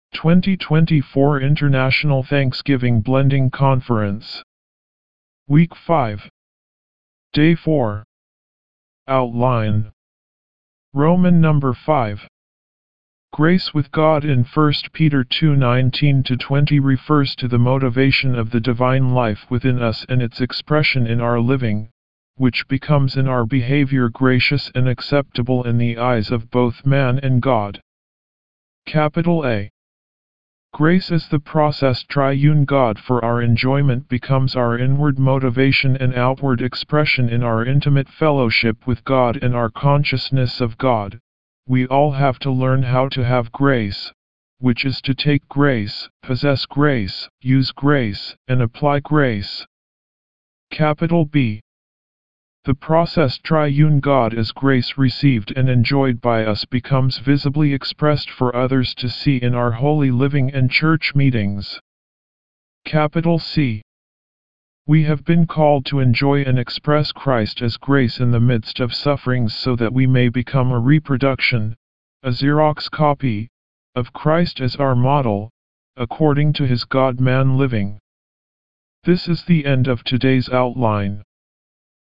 D4 English Rcite：